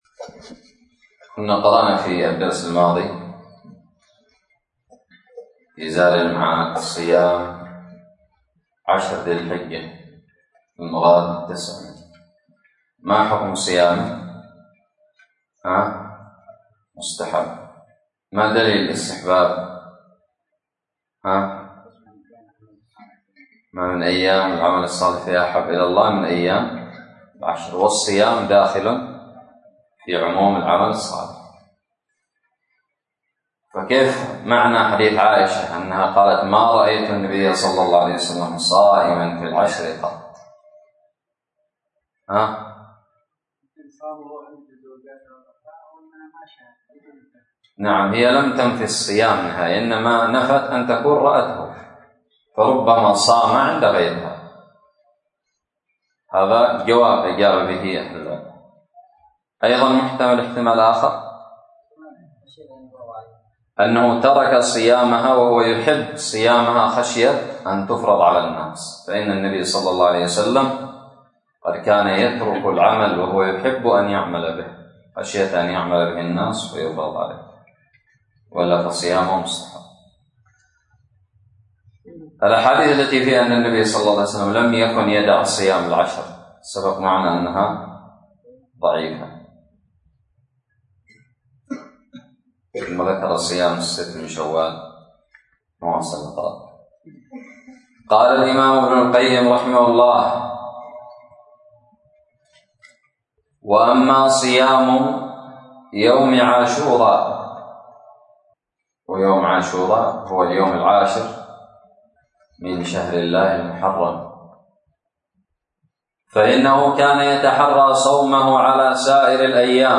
الدرس الثالث عشر من التعليق على فصل هدي النبي صلى الله عليه وسلم في الصوم من زاد المعاد
ألقيت بدار الحديث السلفية للعلوم الشرعية بالضالع